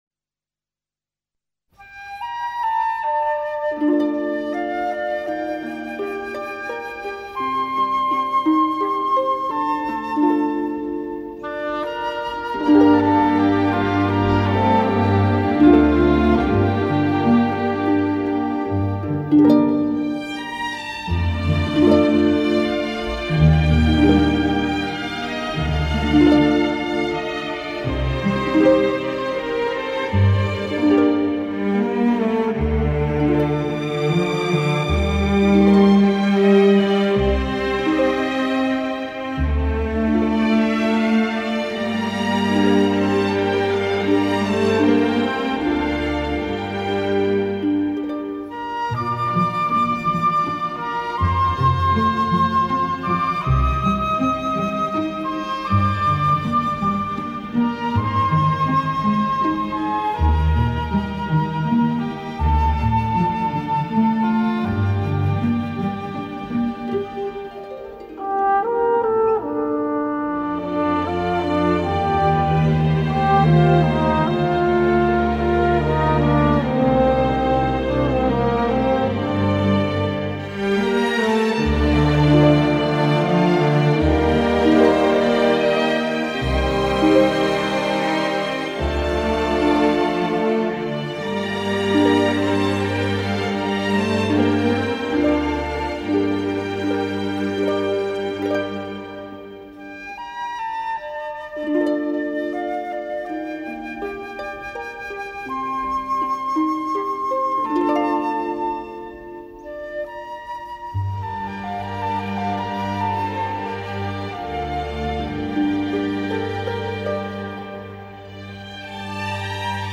Музыка тоже весенняя..)) 12 16